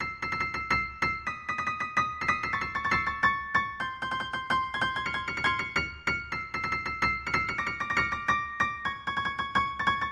钢琴95
Tag: 95 bpm Hip Hop Loops Piano Loops 1.70 MB wav Key : Unknown